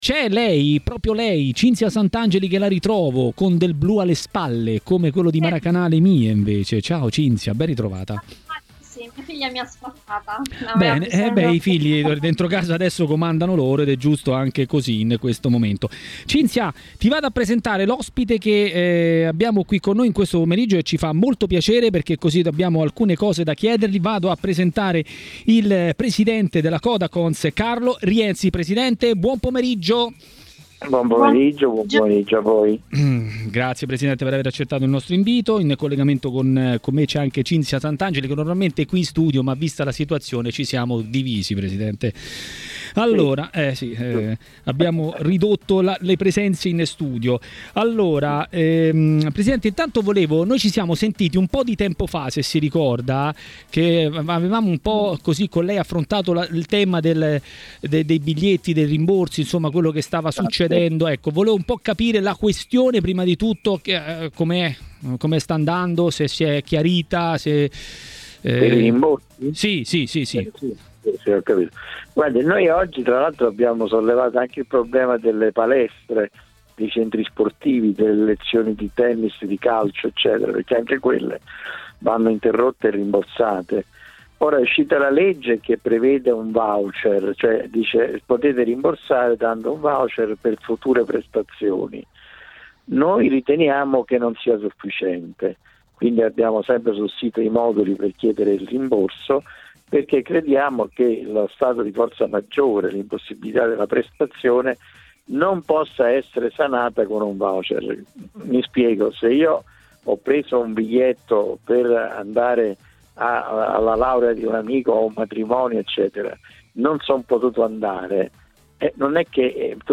A parlare a Maracanà, trasmissione di TMW Radio